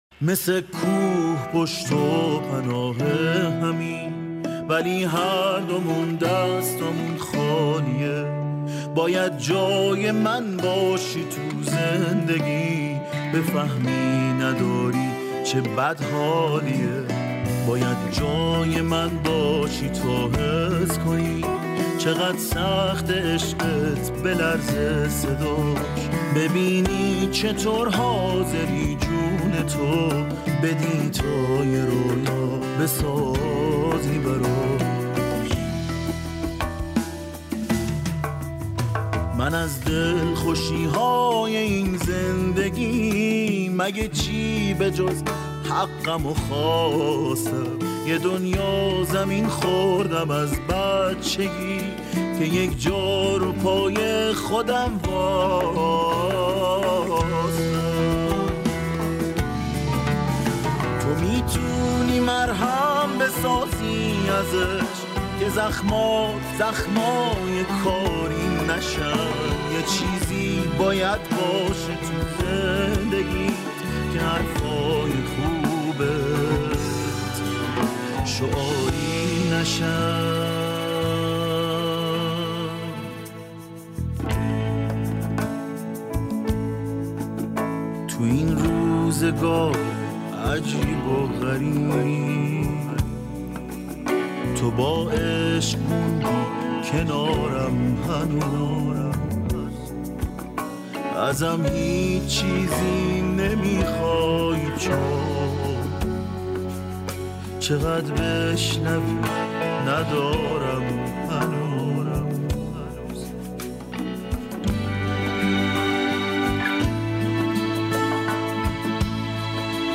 تیتراژ پایانی